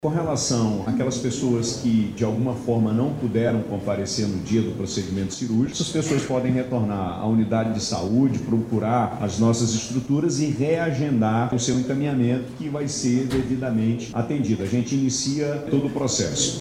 Caso não possam comparecer na data da cirurgia, os pacientes poderão remarcar o procedimentos, destacou, ainda, Wilson Lima.